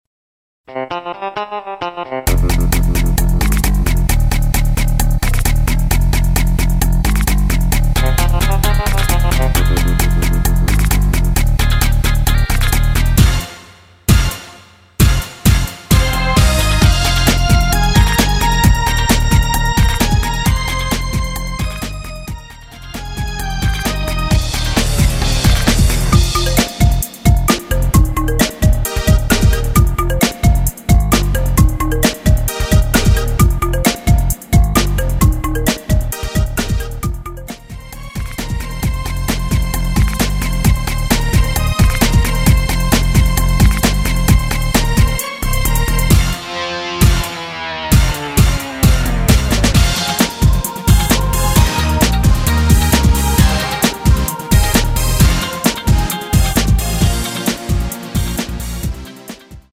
Fm
앞부분30초, 뒷부분30초씩 편집해서 올려 드리고 있습니다.
중간에 음이 끈어지고 다시 나오는 이유는